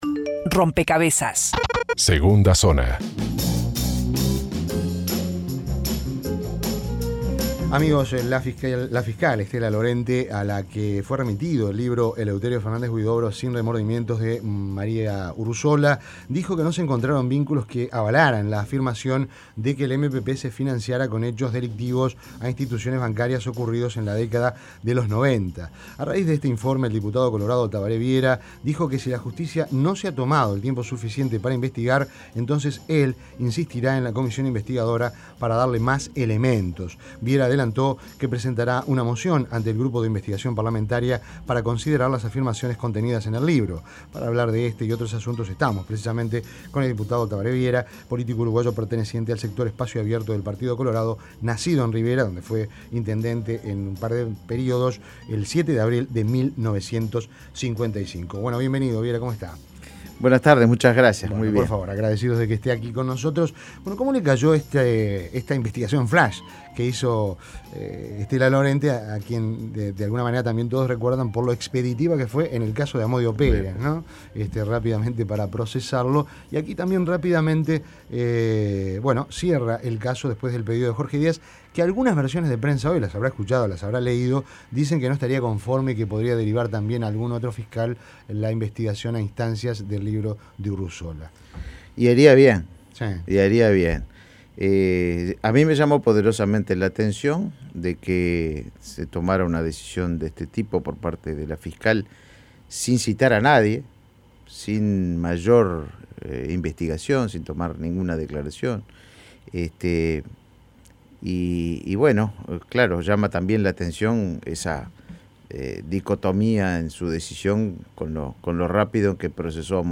Entrevista en Rompkbzas Investigar a los tupamaros Imprimir A- A A+ El fiscal de Corte Jorge Díaz "haría bien" en trasladar a otro fiscal el caso de las tupabandas.